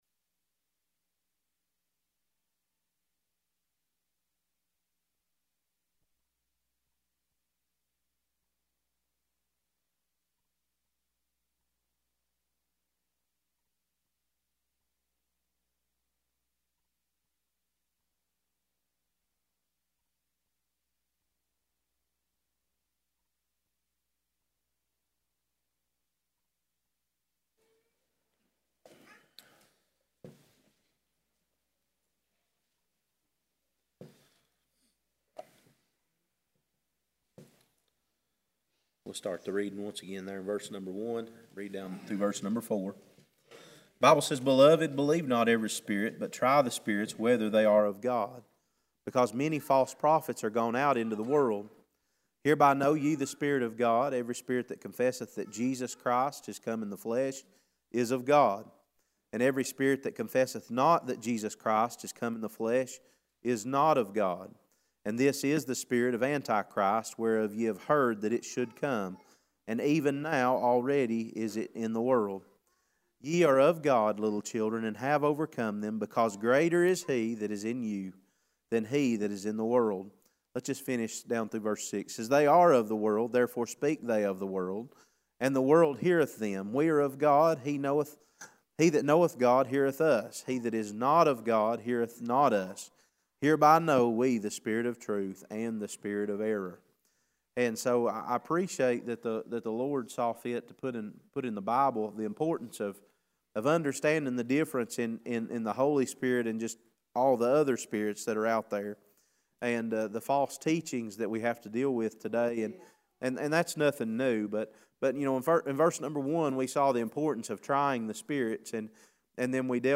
Passage: 1 John 4:1-6 Service Type: Sunday School